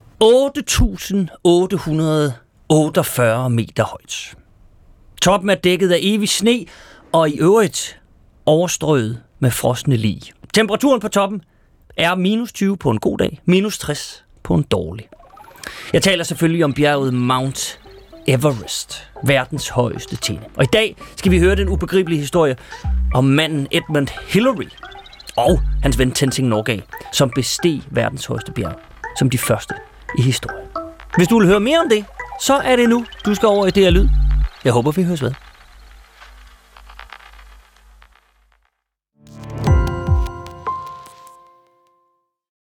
TEASER: Første mand på Mount Everest
Vært: Huxi Bach.